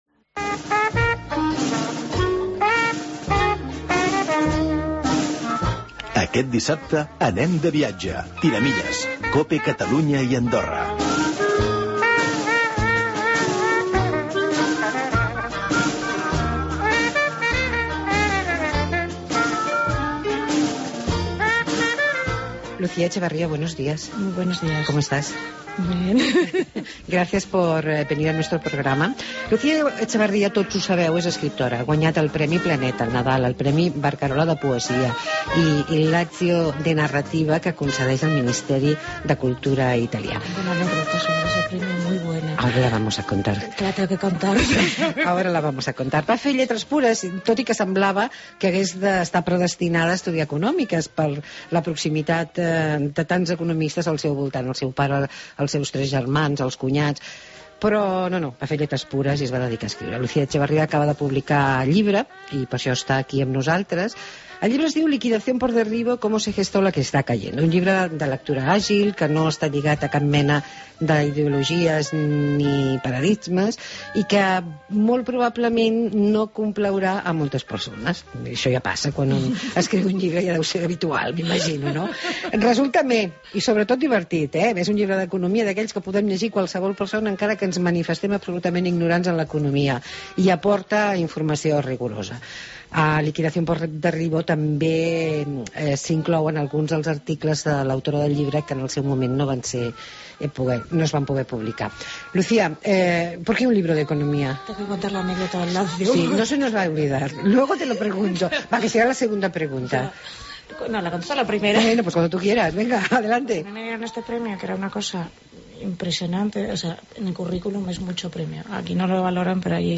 Entrevista a la escritora Lucía Etxebarria